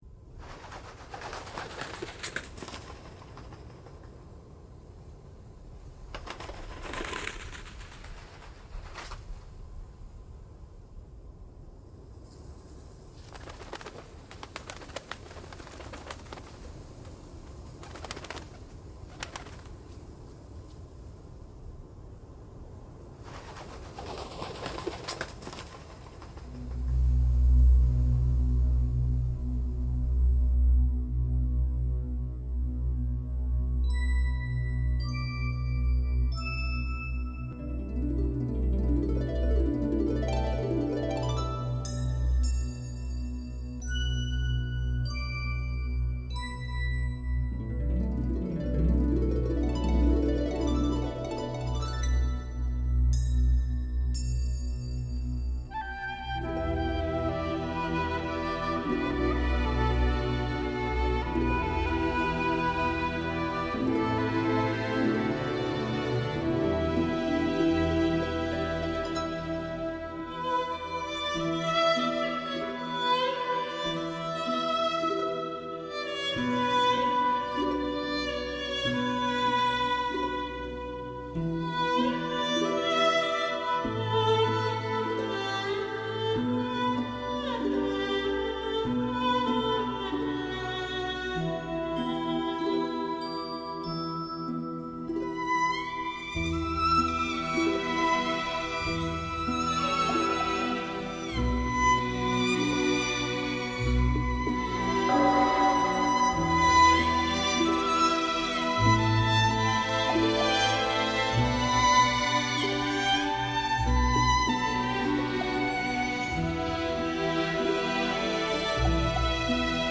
完美打造出顶级质感小提琴经典，